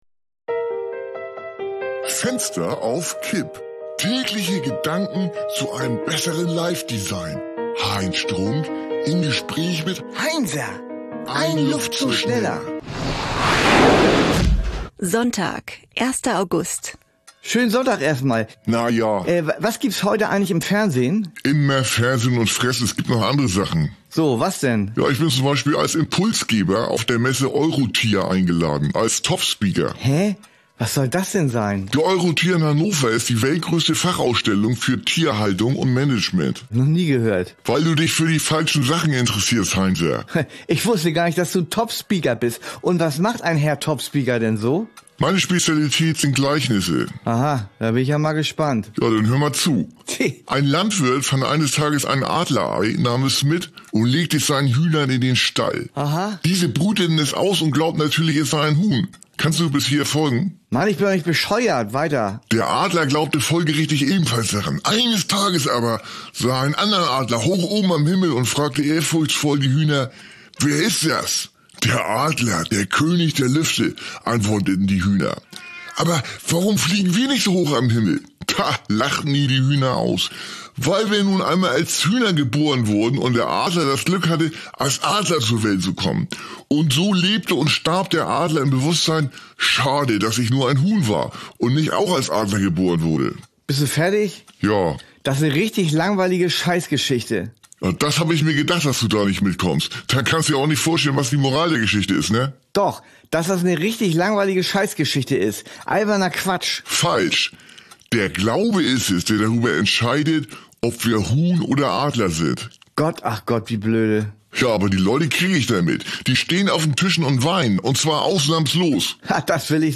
eine Audio-Sitcom von Studio Bummens
Comedy , Nachrichten , Gesellschaft & Kultur